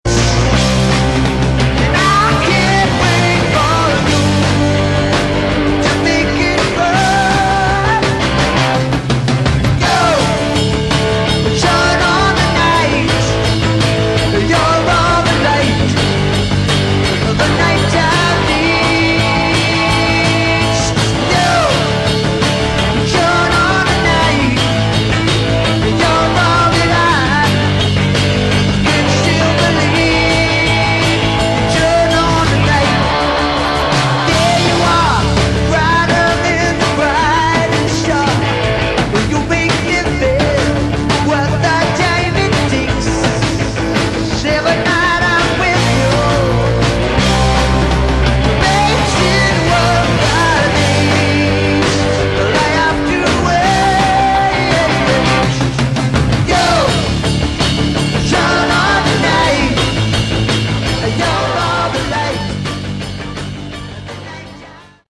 Category: Melodic Rock
lead vocals
keyboards
guitars
bass
drums
live